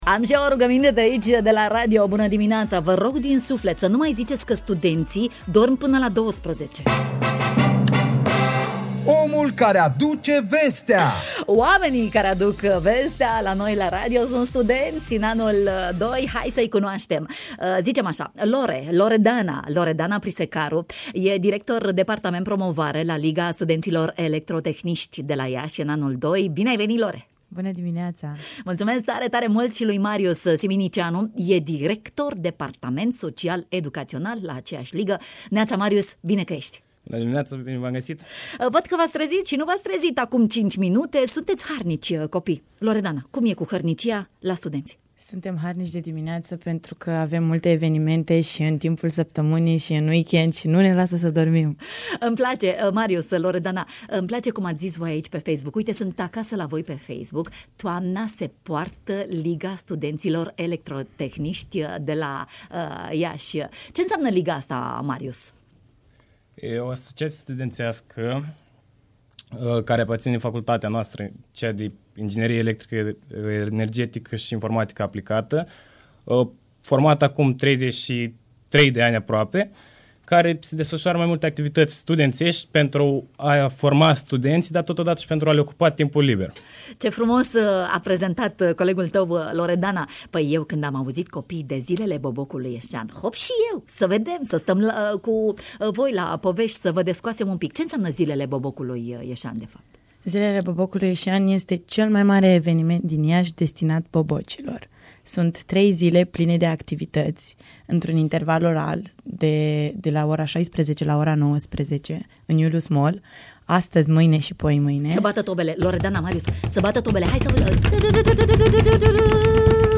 Zilele Bobocului Ieșean, la a VIII-a, eveniment organizat de Asociația Liga Studenților Electrotehniști. Timp de trei zile, 23-25 octombrie 2023, la Iulius Mall Iași din Tudor Vladimirescu, descoperim frumusețea voluntariatului.